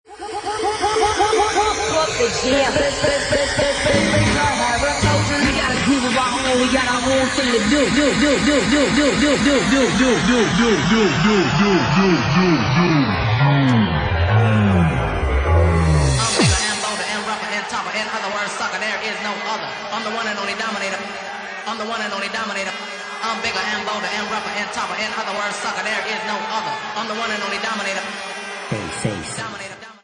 Genre:Bassline House
Bassline House at 51 bpm